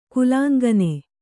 ♪ kulāŋgane